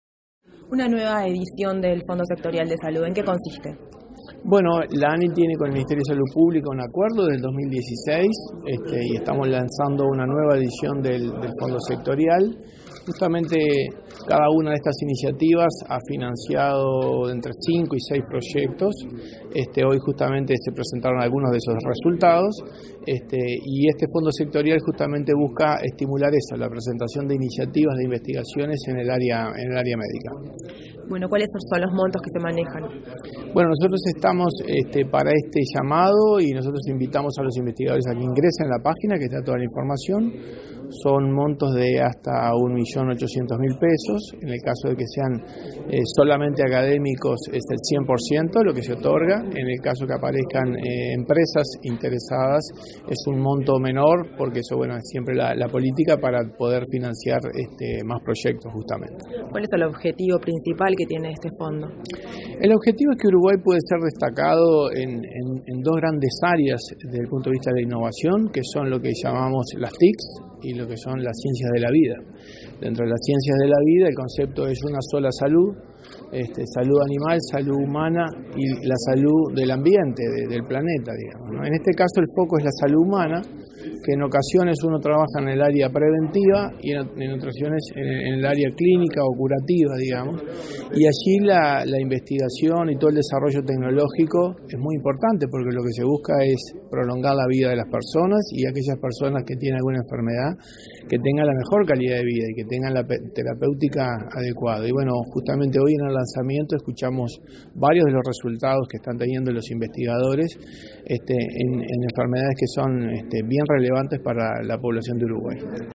Declaraciones a la prensa del director de la Agencia Nacional de Investigación e Innovación, Pablo Caputi
Declaraciones a la prensa del director de la Agencia Nacional de Investigación e Innovación, Pablo Caputi 26/05/2022 Compartir Facebook X Copiar enlace WhatsApp LinkedIn Este 26 de mayo se realizó el lanzamiento del Fondo de Salud en Investigación Clínica. Tras finalizar el evento, el director Pablo Caputi efectuó declaraciones.